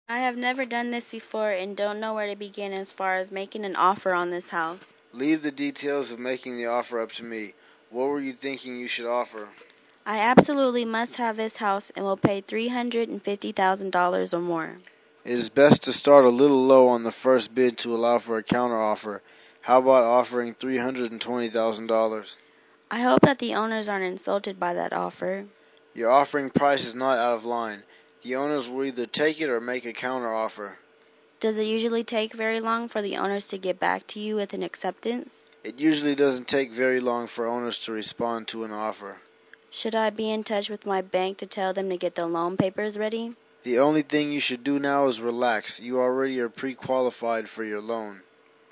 英语情景对话-Making an Offer through a Realtor(3) 听力文件下载—在线英语听力室